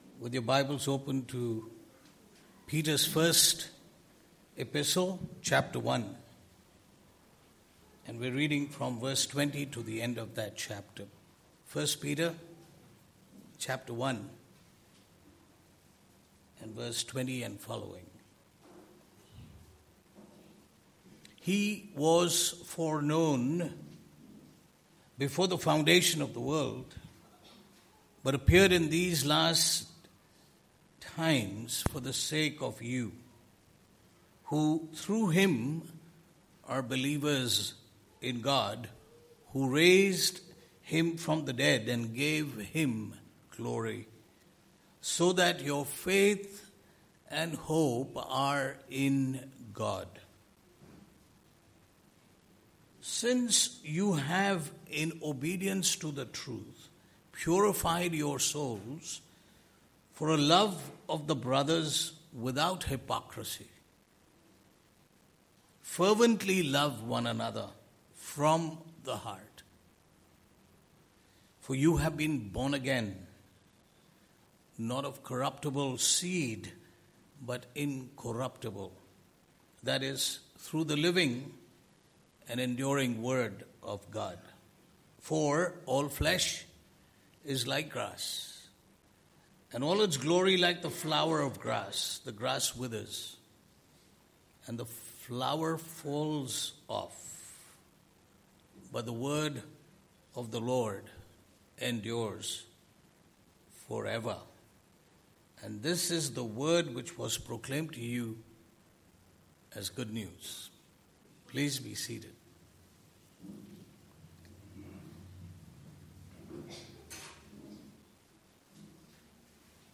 Passage: 1 Peter 1:20-25 Service Type: Sunday Morning